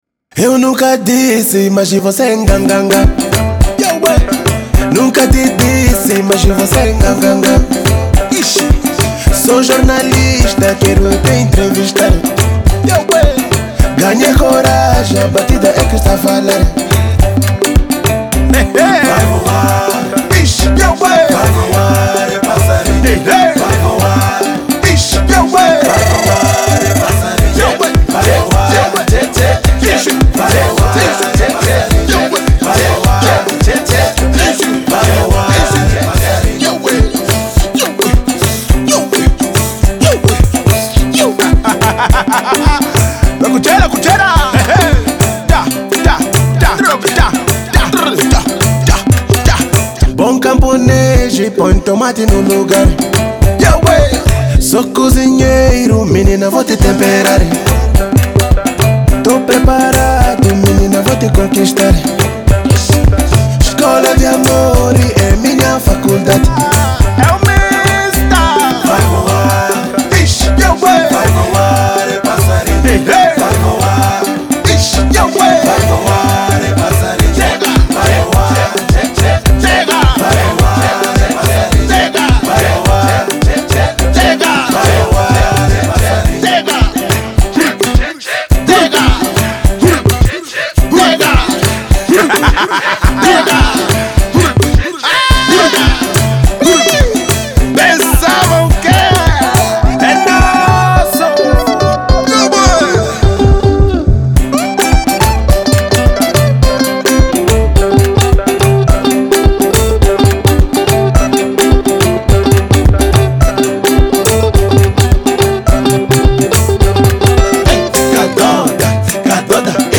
Genero: Kadoda